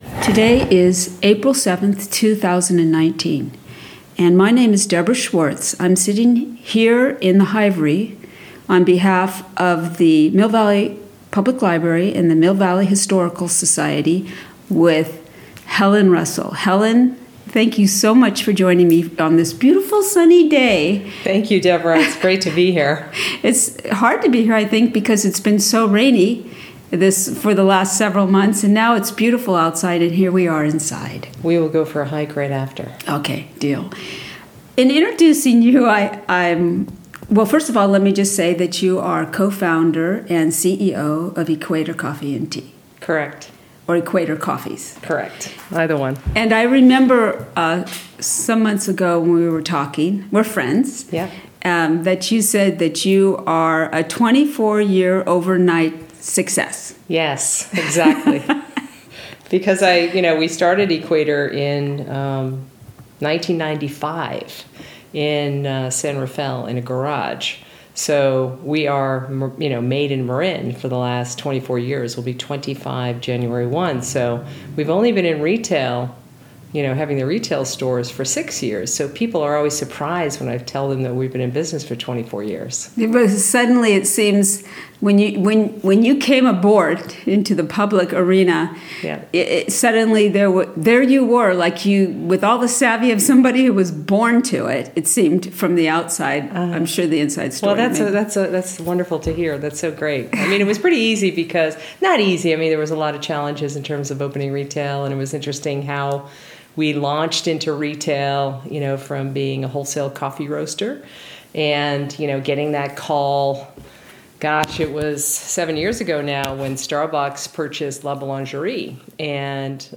Oral history - Local business